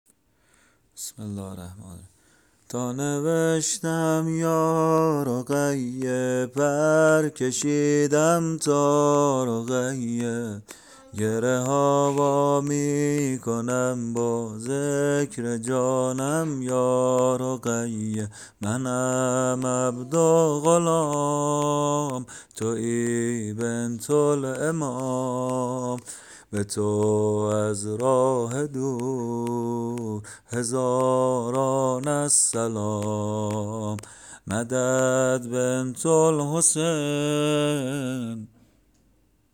به سبک ( ای صفای قلب زارم )